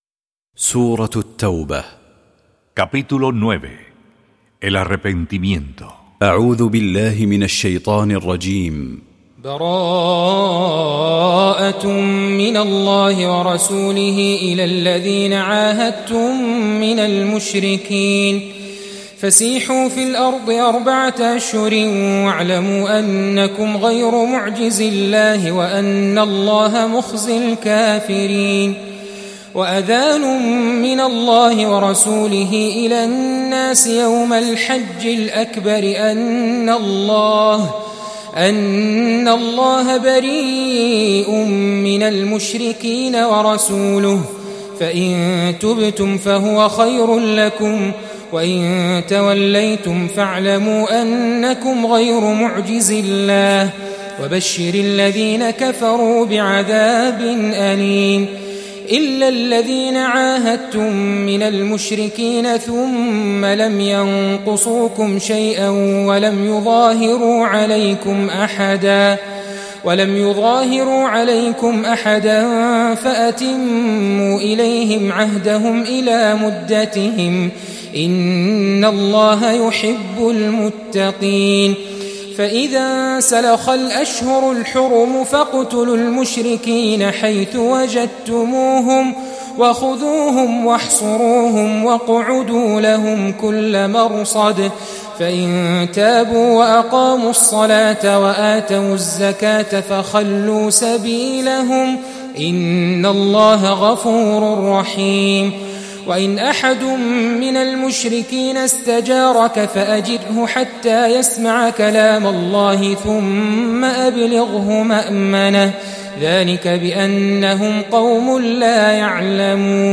EL SAGRADO CORÁN